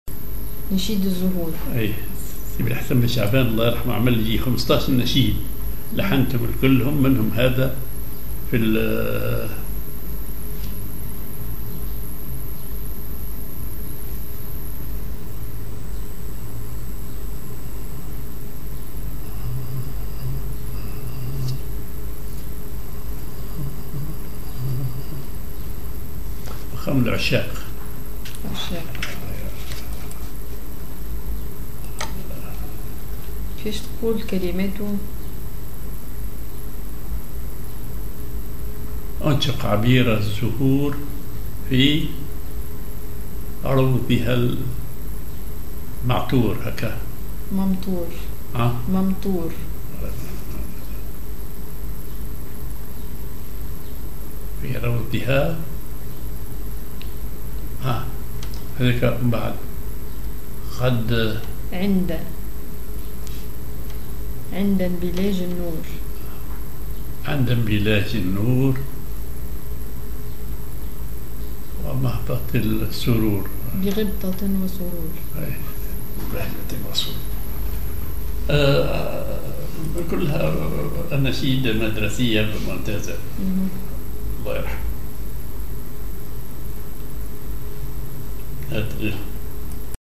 Maqam ar عشاق
genre نشيد